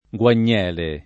gUan’n’$le; non gU#n’-] s. f. pl. — antica alteraz. di vangele pl. f. di vangelo, spec. in formule di giuram. (giurare alle sante Dio guagnele, lat. ad sancta Dei evangelia) e in escl. di meraviglia (per le g.!, alle sante g.!)